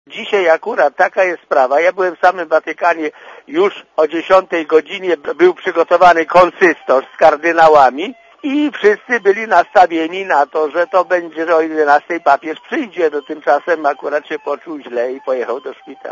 Mówi ojciec